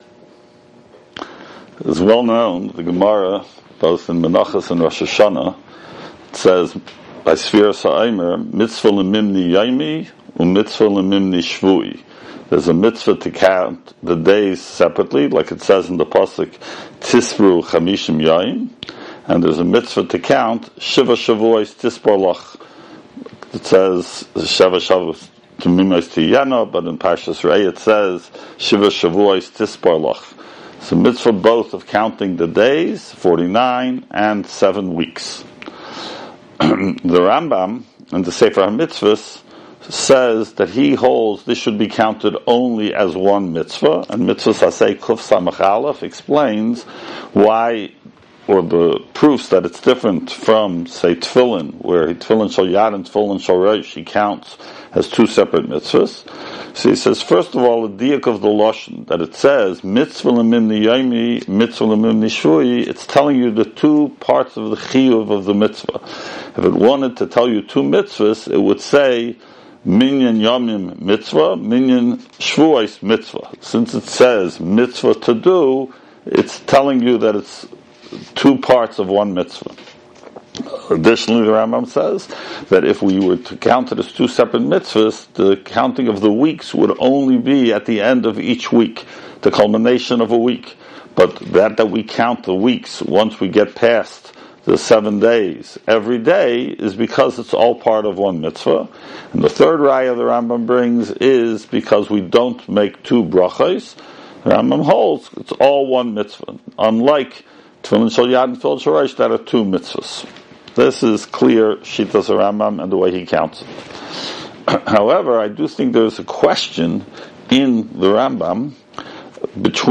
Weekly Alumni Shiur - Ner Israel Rabbinical College